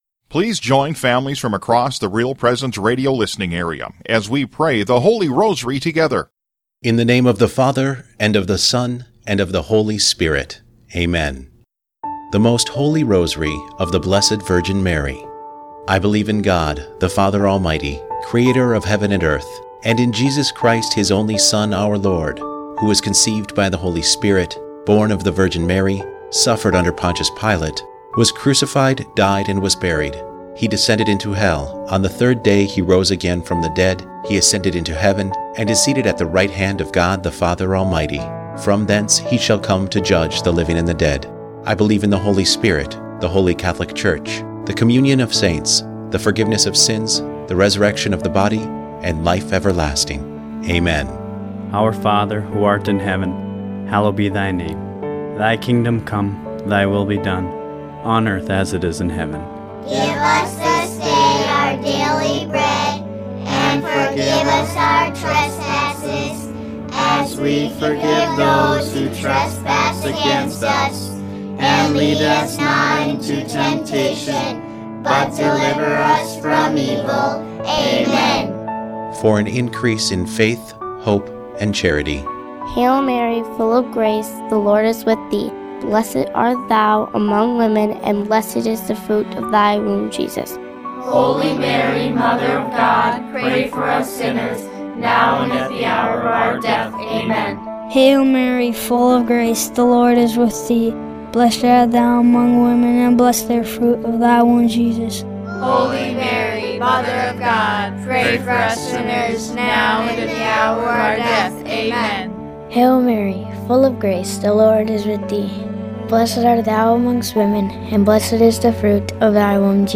We invite you to pray our special presentation of the Sorrowful Mysteries of the RPR Family Rosary. We visited families throughout our listening area and recorded this beautiful prayer with moms, dads, and their children.